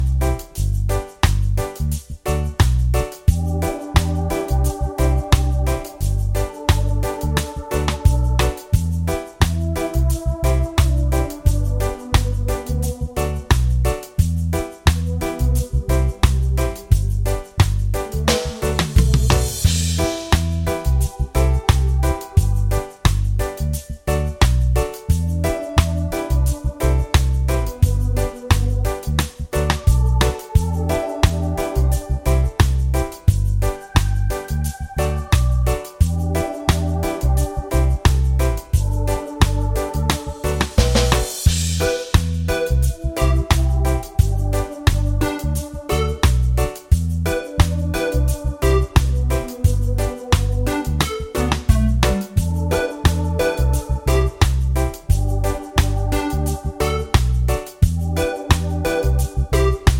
Minus Main Guitar For Guitarists 3:33 Buy £1.50